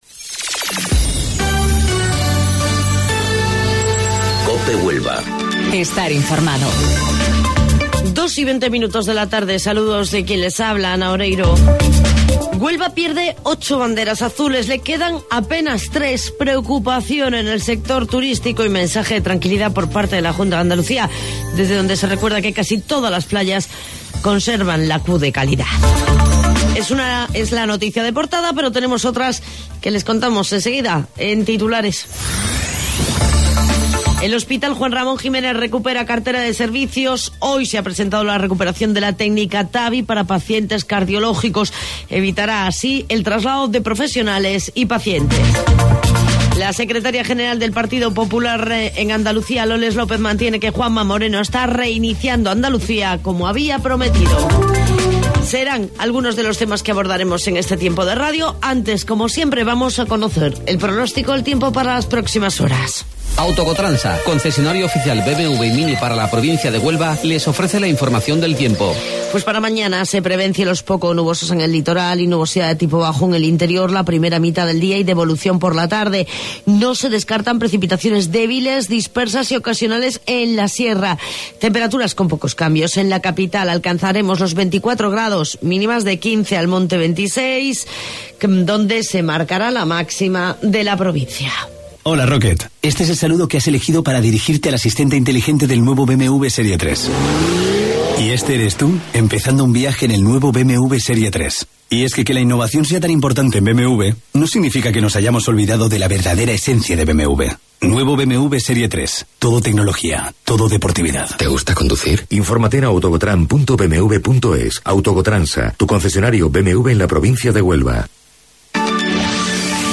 AUDIO: Informativo Local 14:20 del 8 de Mayo